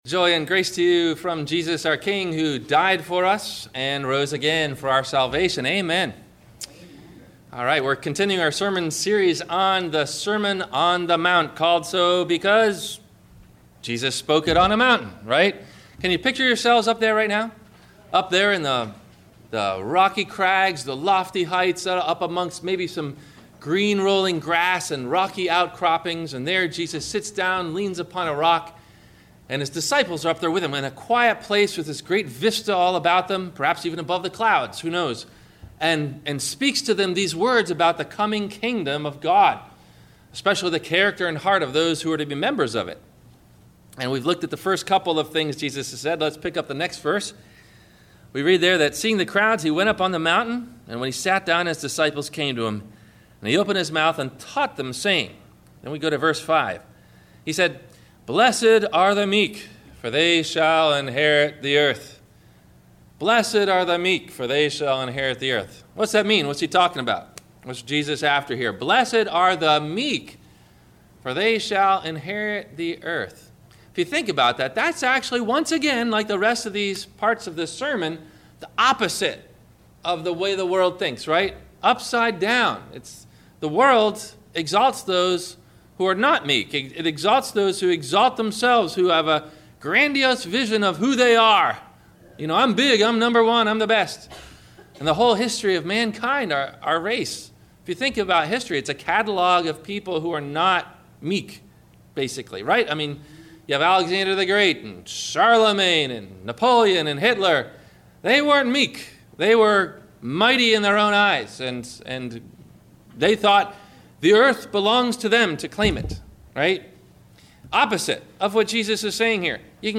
Questions to think about before you hear the Sermon: